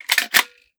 12ga Pump Shotgun - Load Shells 002.wav